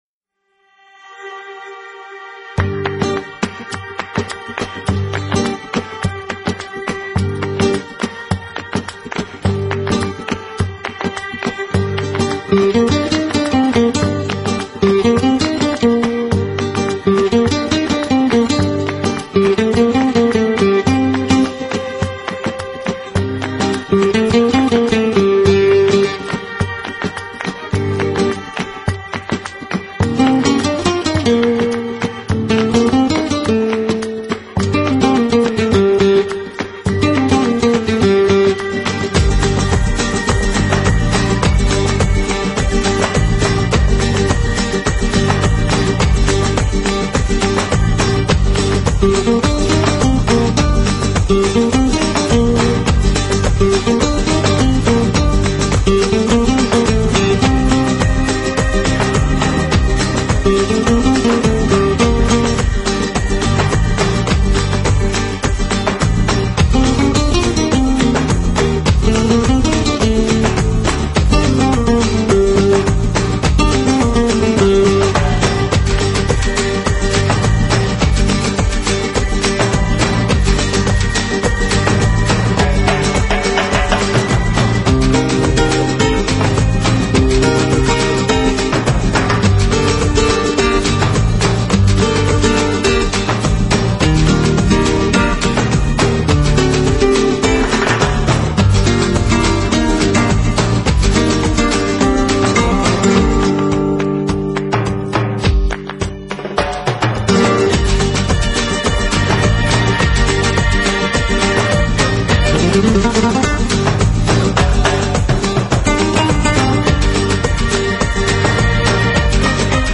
音乐风格: 乐器/弗拉门戈吉他
明快的曲风，没有丝毫拖沓的起转承和，起点就是高潮，高潮既是结束。